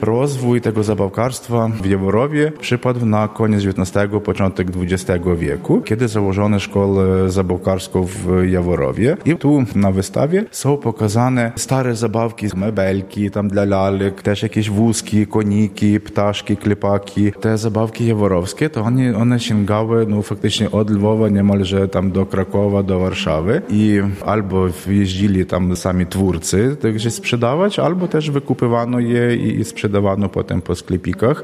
lok_Radruz_wernisaz.mp3